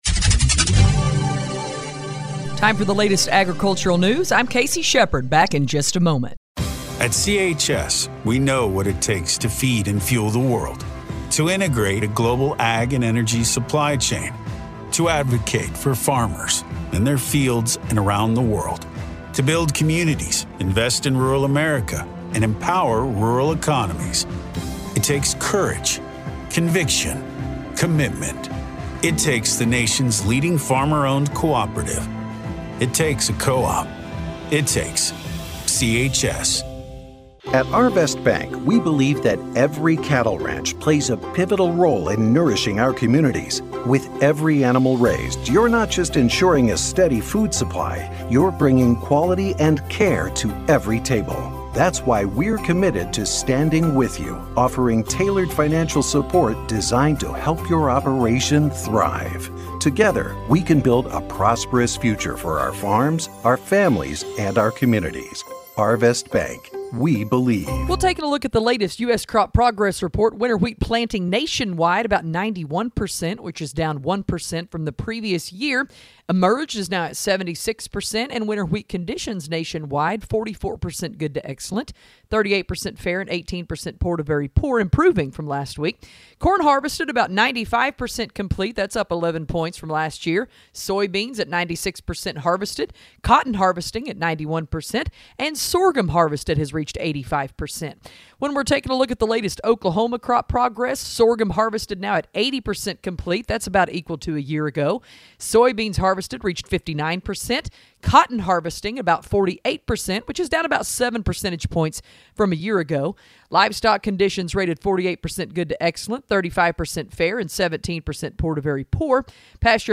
if you missed this morning's Farm News - or you are in an area where you can't hear it- click below for this morning's Farm news